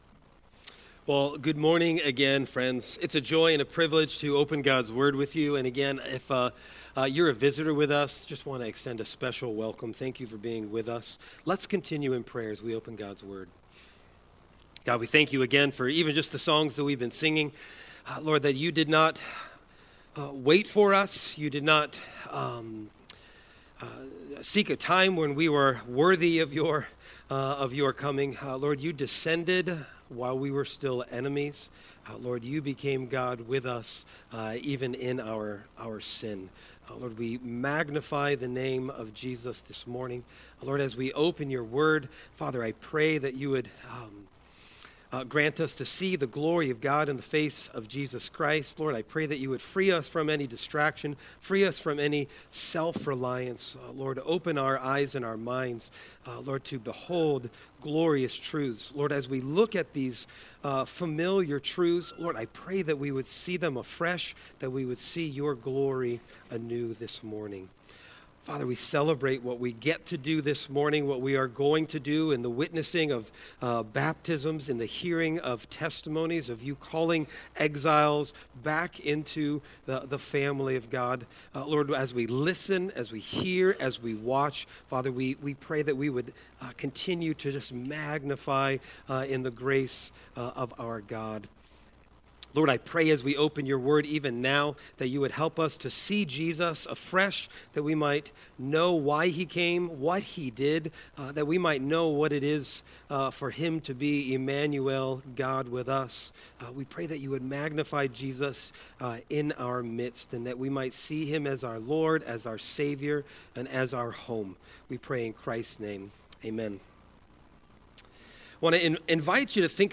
Scripture Reading: Isaiah 9:2-7
Sermon Text: Matthew 1:18–23